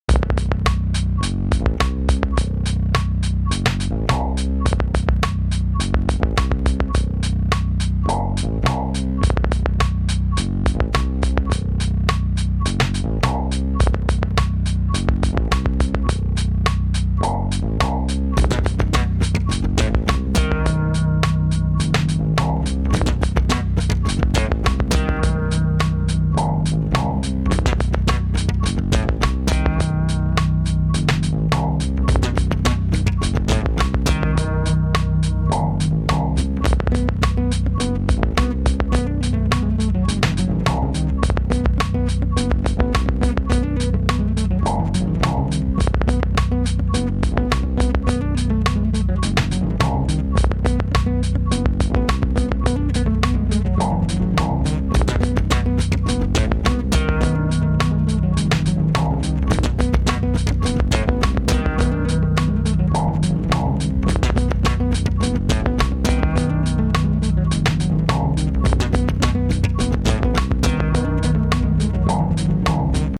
home of the daily improvised booty and machines -